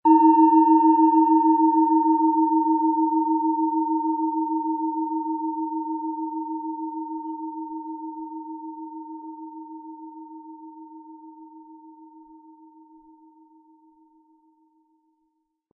Planetenschale® Zeitlos schwingen und leben & Körper und Seele verbinden mit Wasserstoffgamma, Ø 12 cm, 180-260 Gramm inkl. Klöppel
Im Sound-Player - Jetzt reinhören können Sie den Original-Ton genau dieser Schale anhören.
Mit dem beigelegten Klöppel können Sie je nach Anschlagstärke dominantere oder sanftere Klänge erzeugen.
SchalenformBihar
MaterialBronze